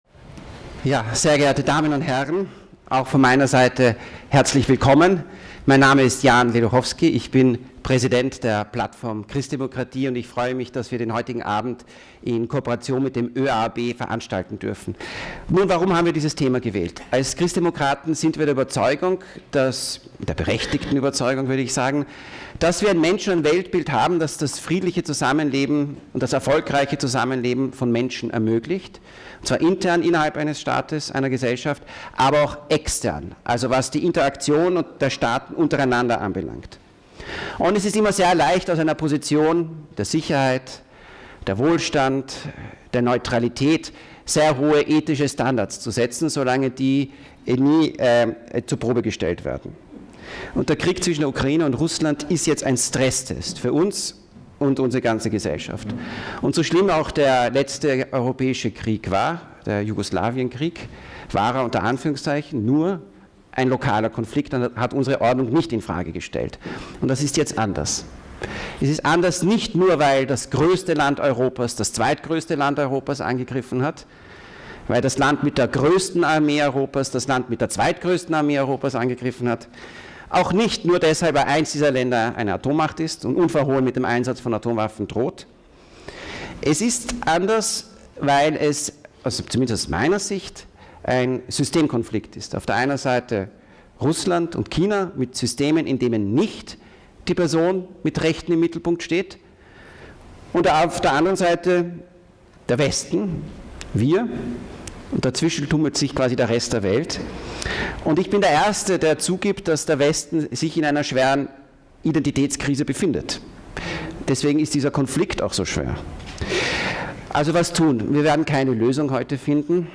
[Tag 455 - 24 Mai 2024] Oberst Markus Reisner - Impulsvortrag zum Krieg in der Ukraine.mp3